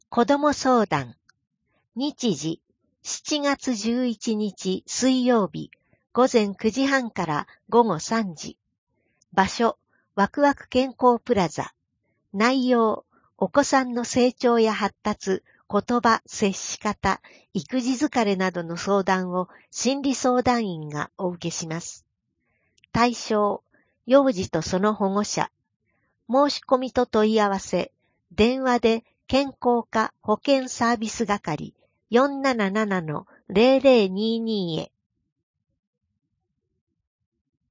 声の広報（平成30年7月1日号）